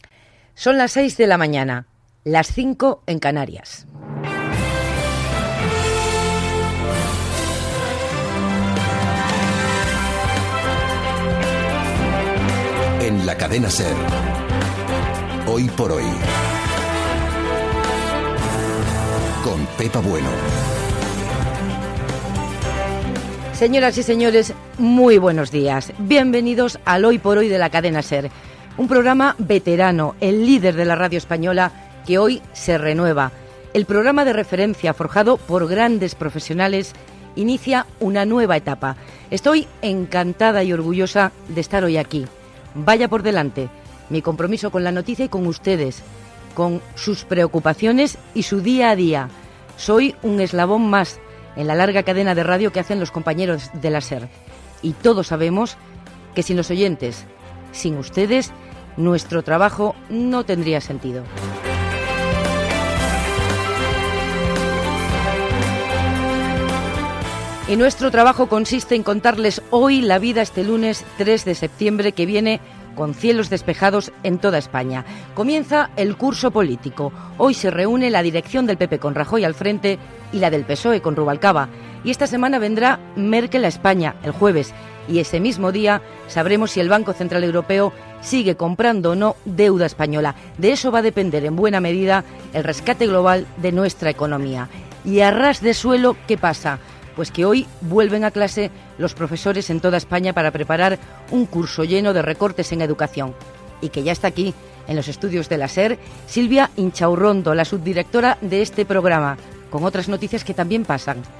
Hora, careta del programa, presentació de la nova etapa del programa, resum informatiu
Info-entreteniment